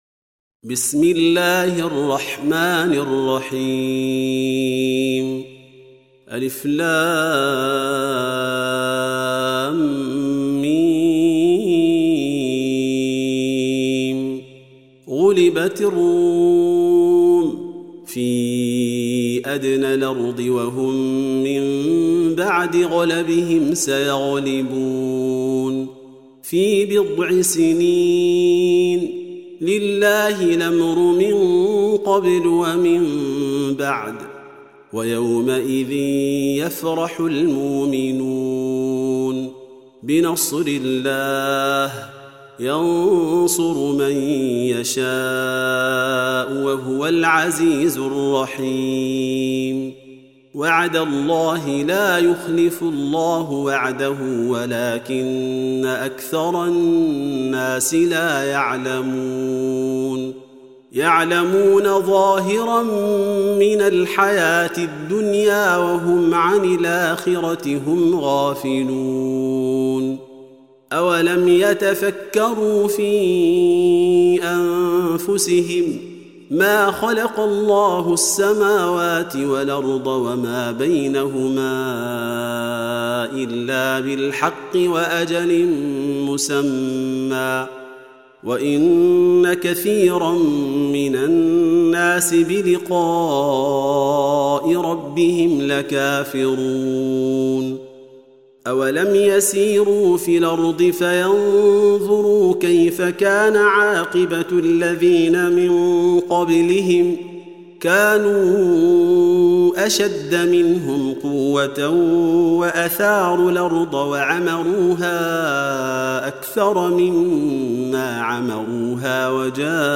Audio Quran Tarteel Recitation
Surah Sequence تتابع السورة Download Surah حمّل السورة Reciting Murattalah Audio for 30. Surah Ar�R�m سورة الرّوم N.B *Surah Includes Al-Basmalah Reciters Sequents تتابع التلاوات Reciters Repeats تكرار التلاوات